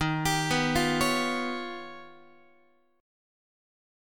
Listen to D#+9 strummed